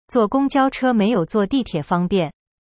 se lee como: dìtiě